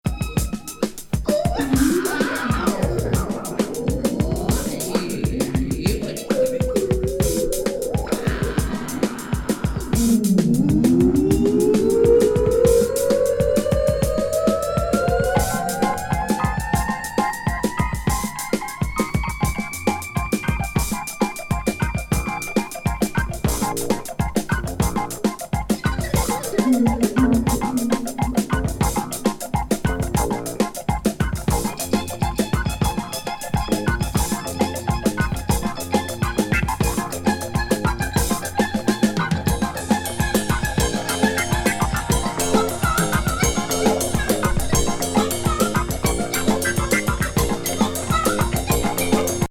奇才バイオリン奏者のソロ1ST73年作。